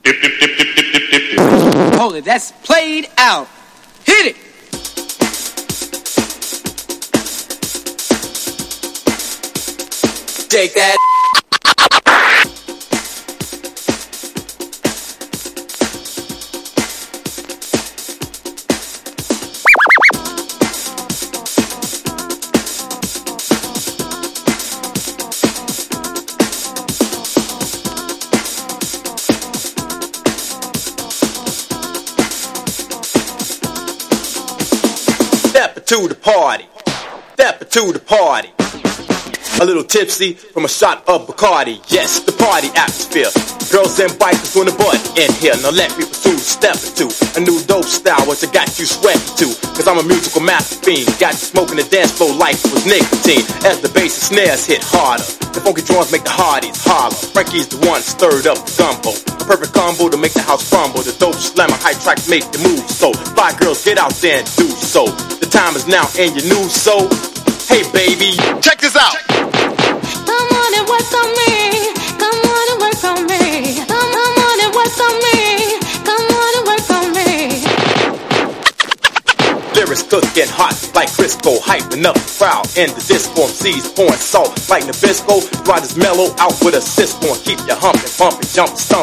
DEEP HOUSE / EARLY HOUSE